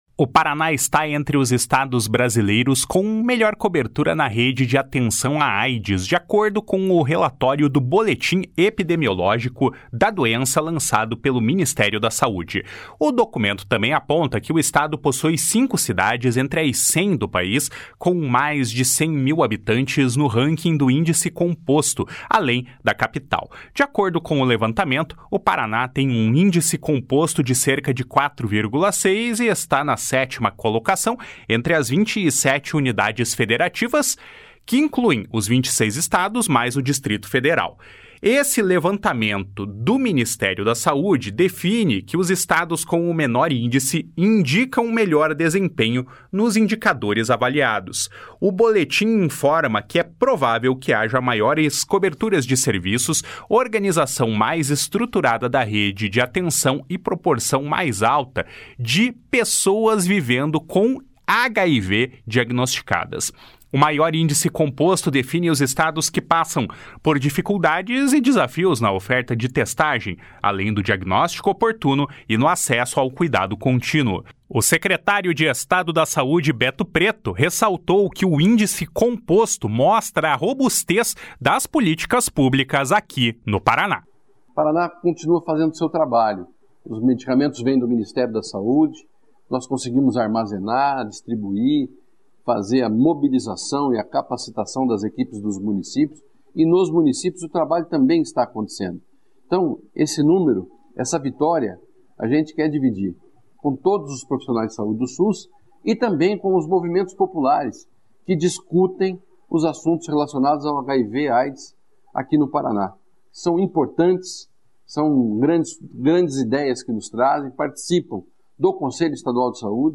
O secretário de Estado da Saúde, Beto Preto, ressaltou que o índice composto mostra a robustez das políticas públicas no Paraná. // SONORA BETO PRETO //